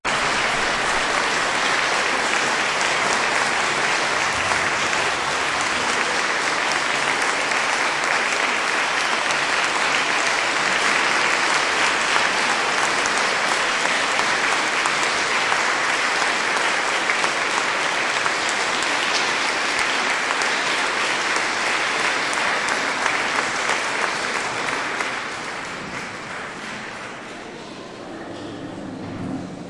随机的 "掌声" 附近教堂里的大量人群
描述：掌声大人群int教堂附近。弗拉克
Tag: 教堂 人群 掌声 INT